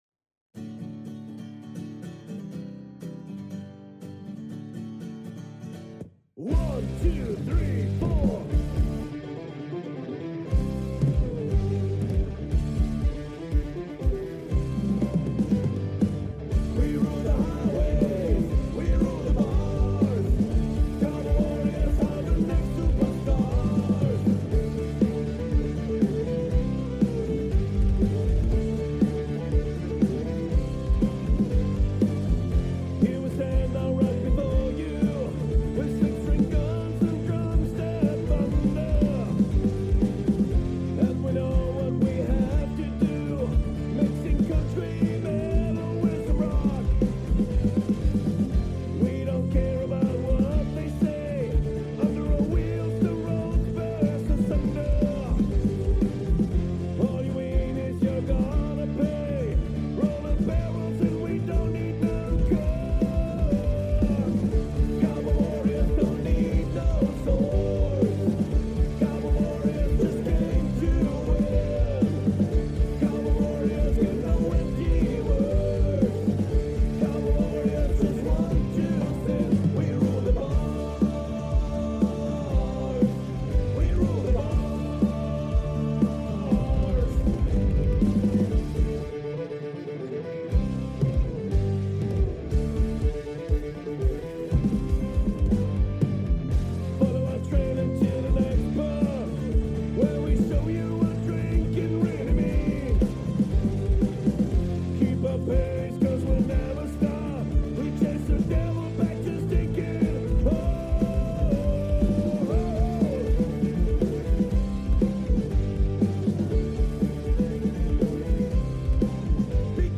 cw_cw_silent_outside.mp3